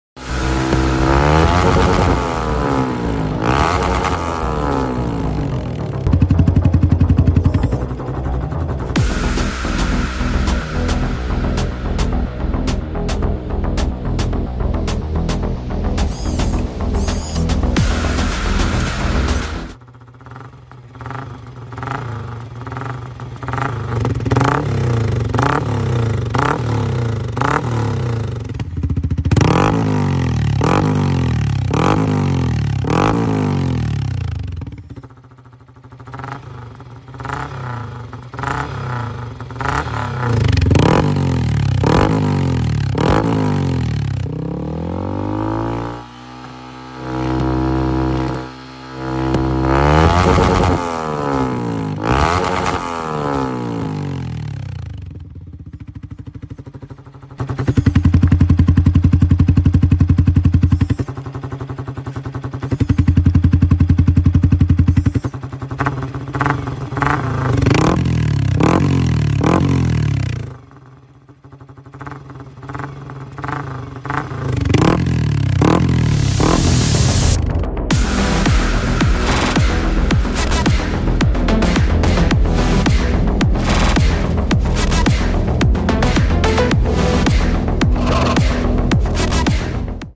Tridente Cerberus Installed on Yamaha